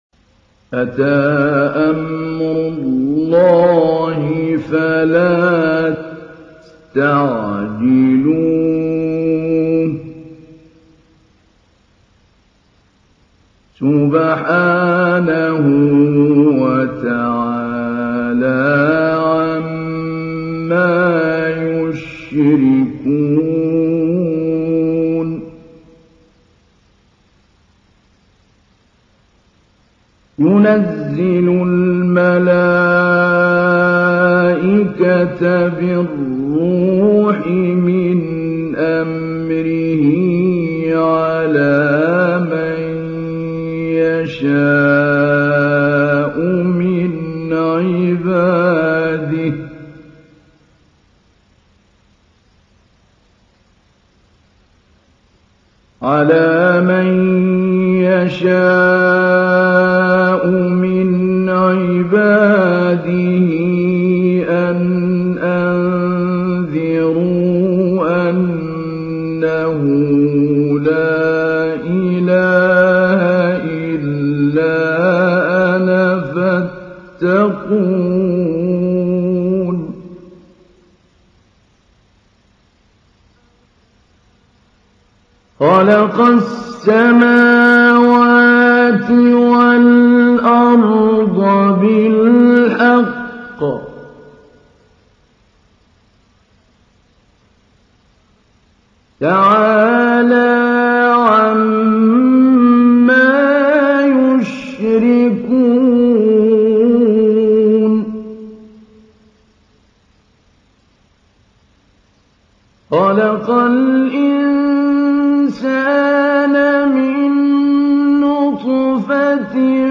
تحميل : 16. سورة النحل / القارئ محمود علي البنا / القرآن الكريم / موقع يا حسين